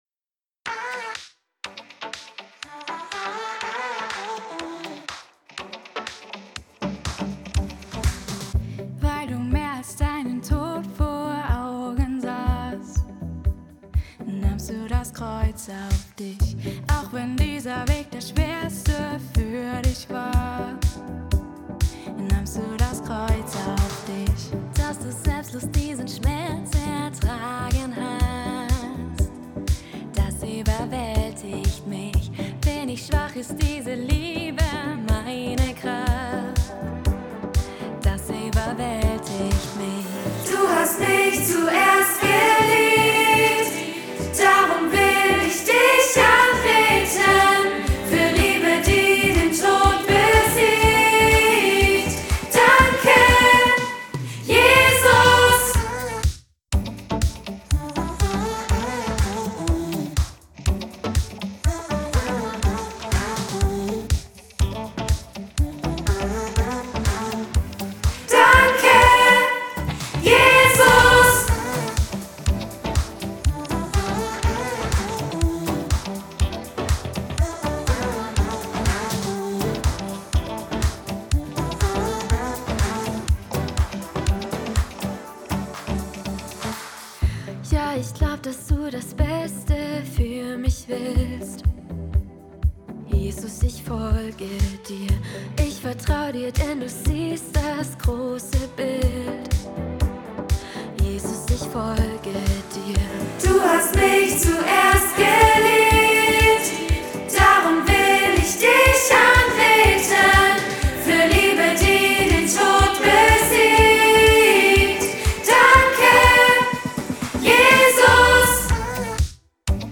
Audiospur Sopran
Zwei- bis Dreistimmige Chornoten